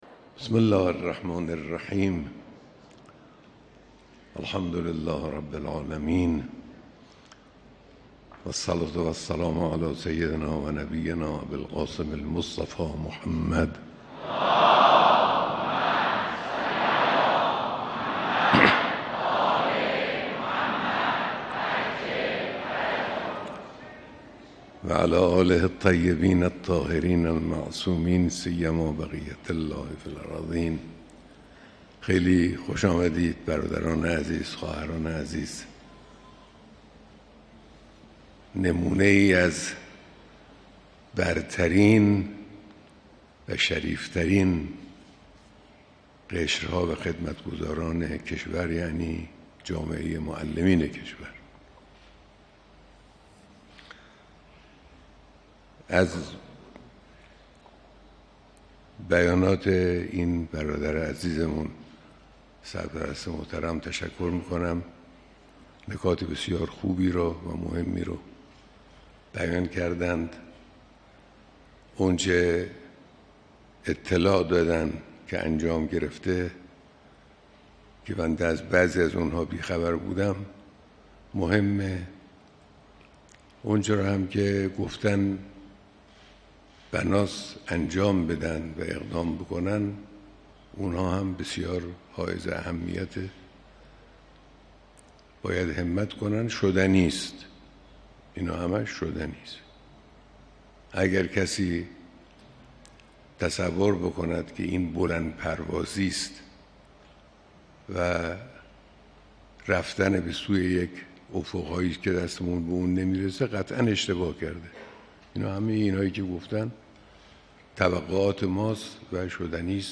بیانات در دیدار معلمان و فرهنگیان سراسر کشور